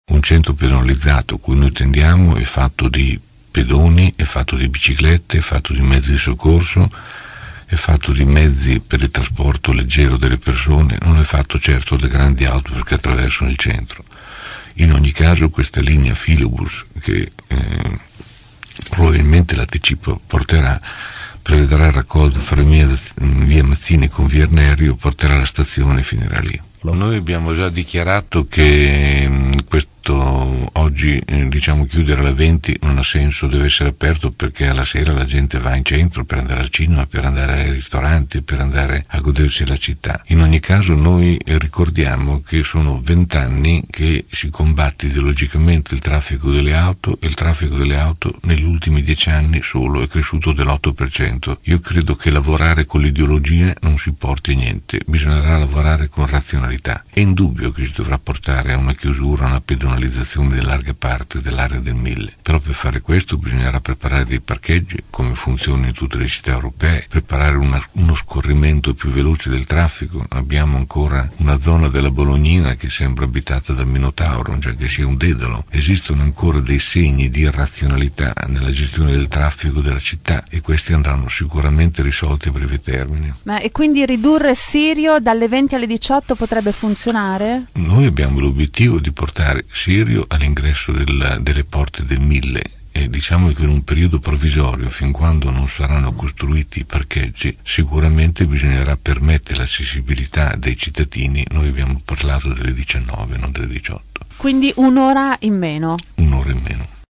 ospite questa mattina dei nostri studi.